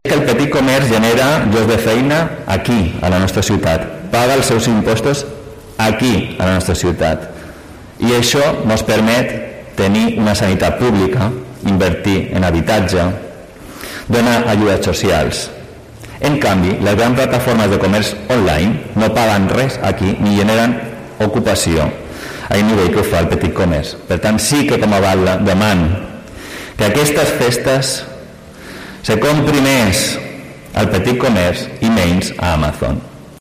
José Hila, alcalde de Palma